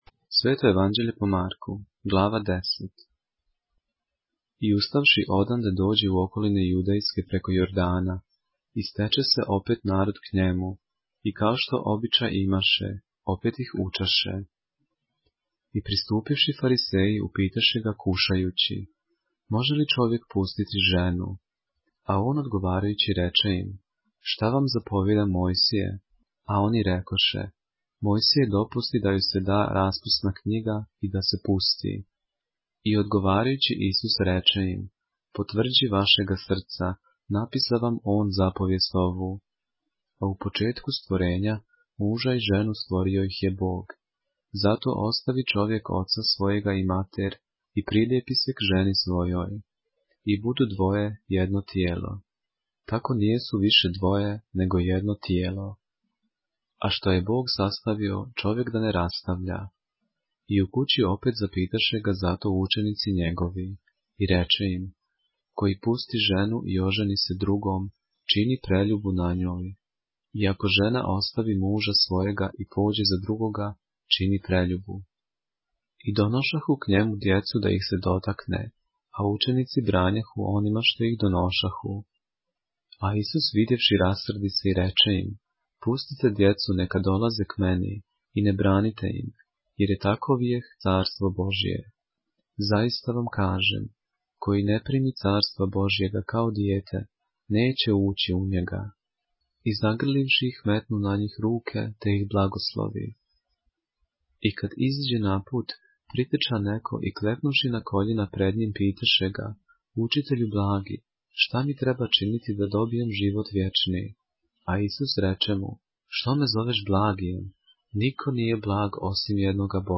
поглавље српске Библије - са аудио нарације - Mark, chapter 10 of the Holy Bible in the Serbian language